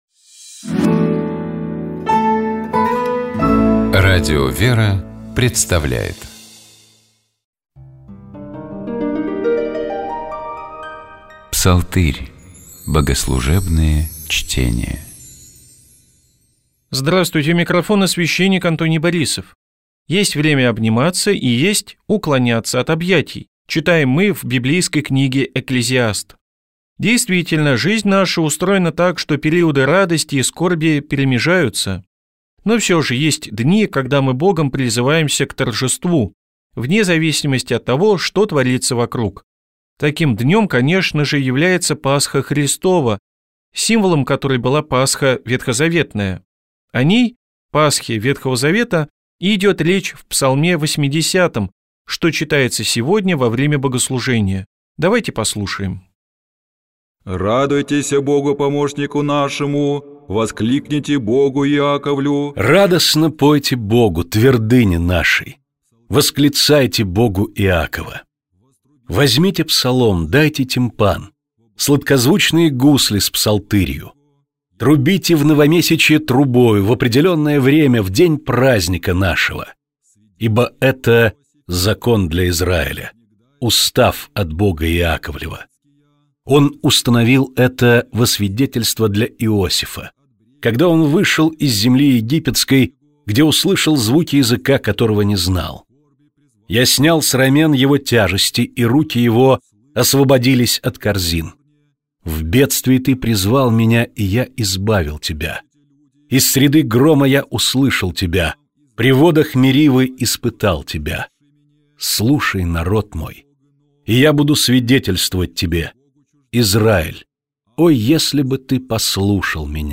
Псалом 80. Богослужебные чтения